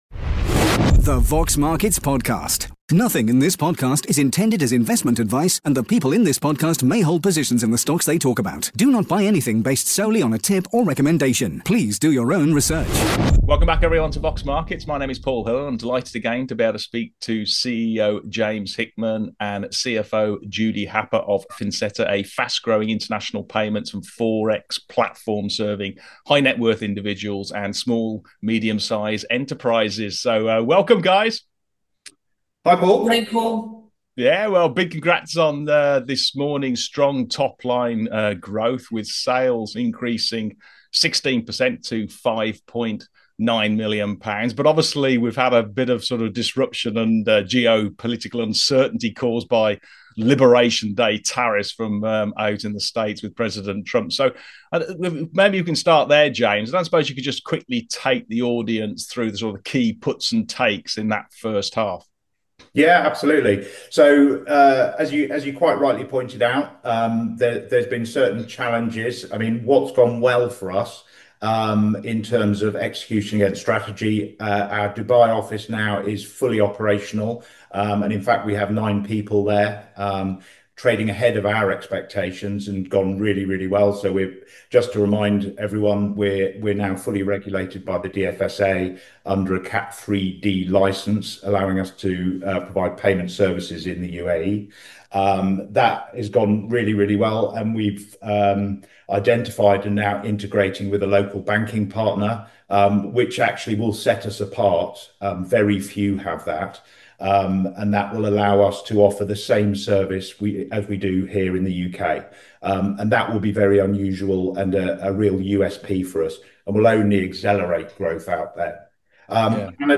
upbeat interview